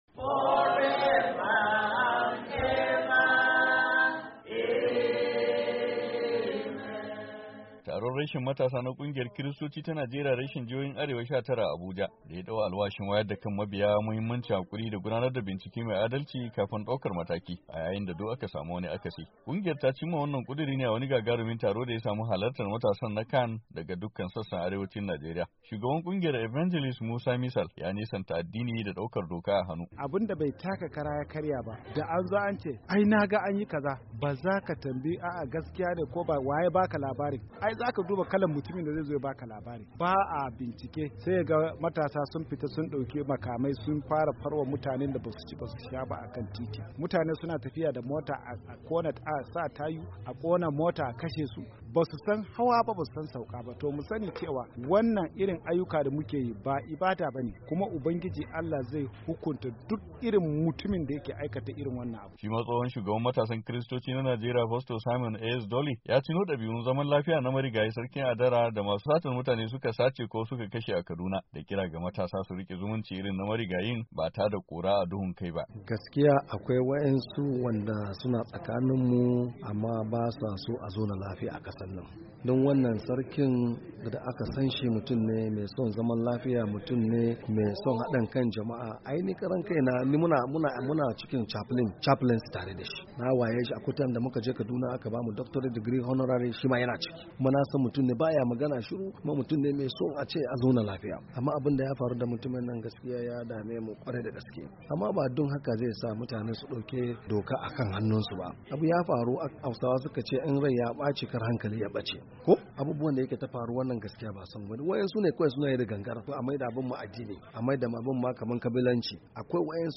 Kungiyar ta cimma wannan kuduri ne a wani gagarumin taro da ya samu halartar matasan na “CAN” daga dukkan sassan arewacin Najeriya.